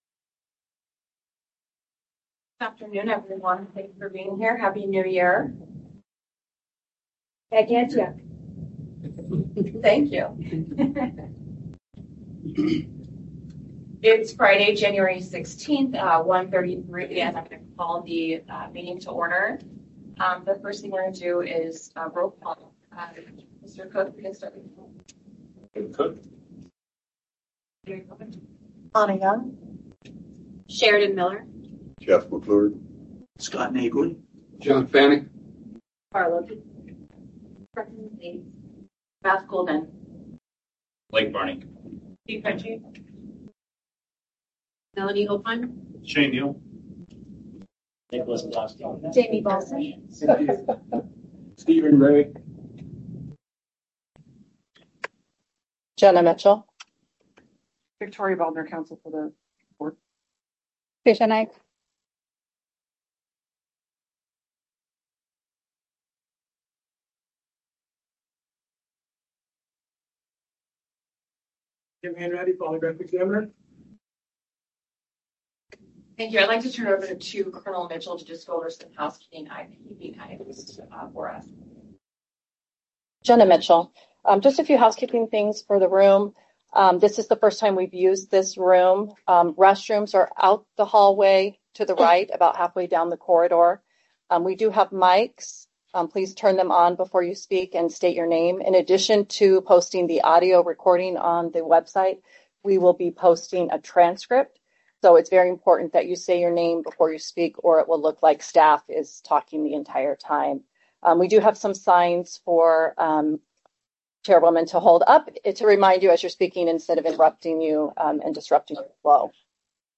SOMB Meeting - January 16, 2026 | Department of Public Safety
Notice of Public Meeting